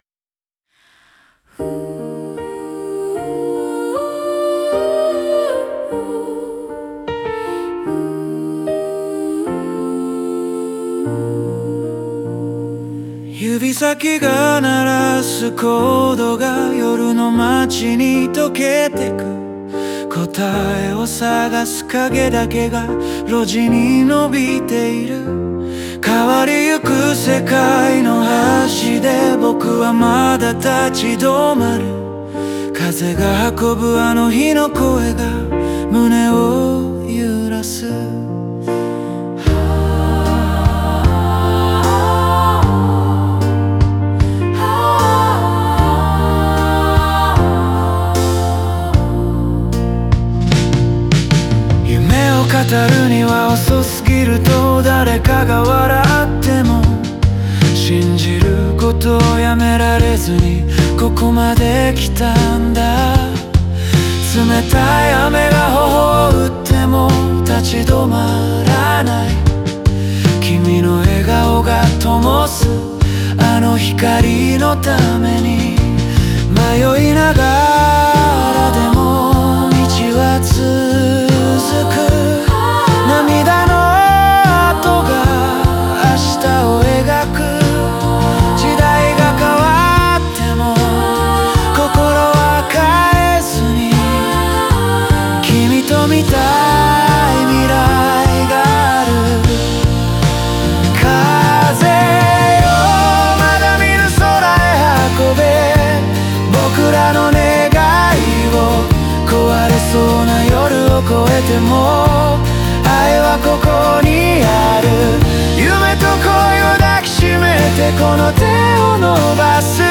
オリジナル曲♪
静かなギターの音色から始まり、徐々に広がるコーラスとストリングスが、迷いから覚悟への変化を音で表現している。